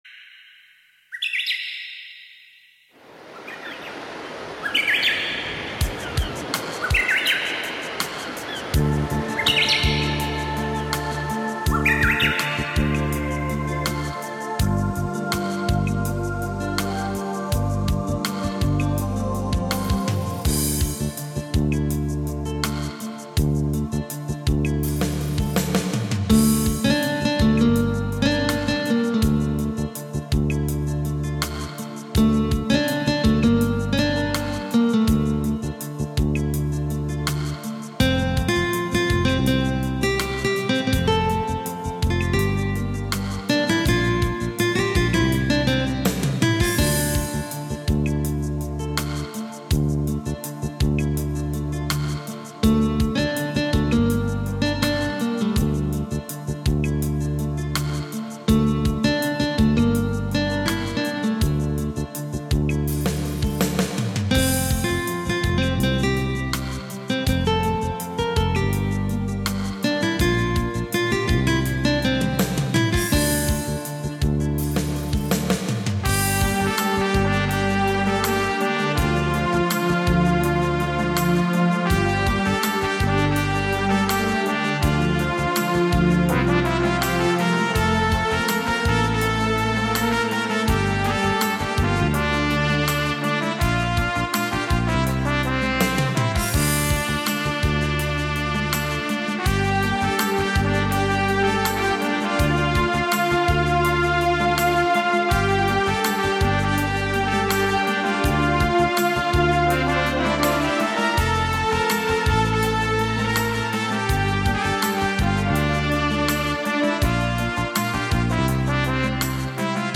שמח ונעים לאוזן י אנונימי י    הודעה אחרונה